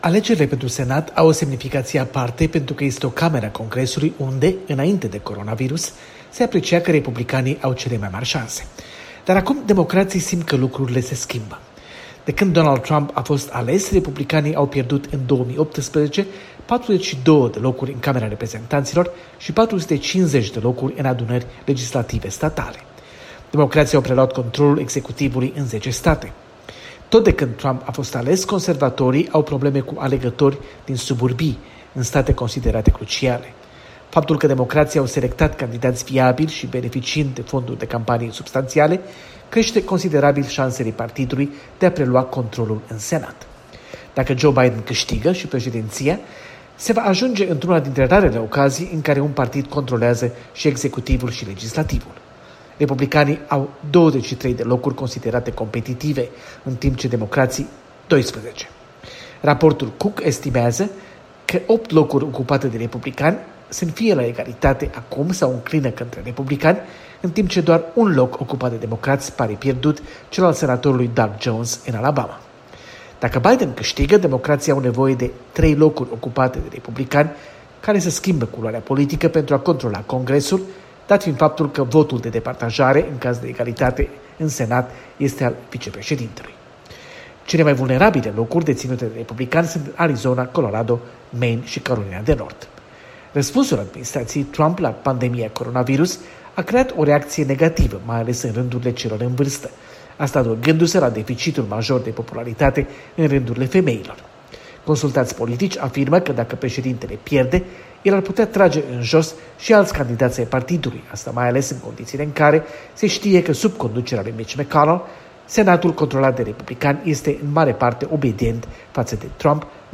Corespondență de la Washington: alegerile pentru Senat